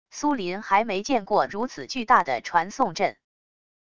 苏林还没见过如此巨大的传送阵wav音频生成系统WAV Audio Player